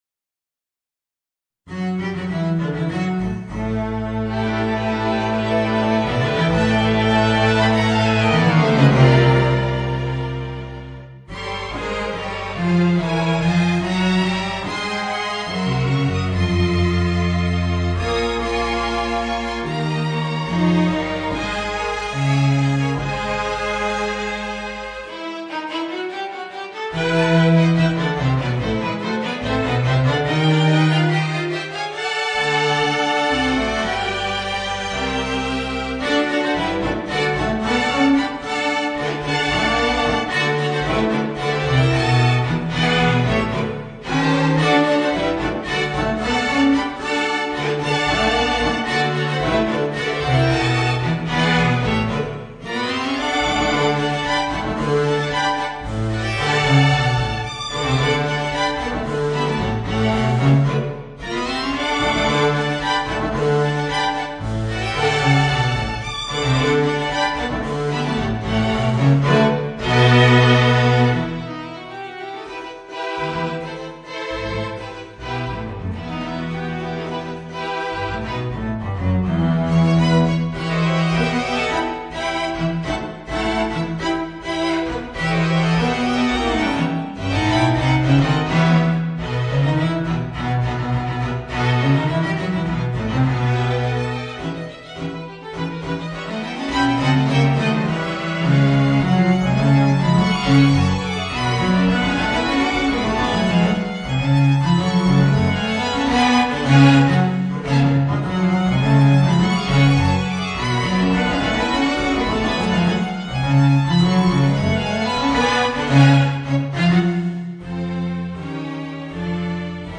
Voicing: String Quintet